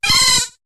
Cri de Wattouat dans Pokémon HOME.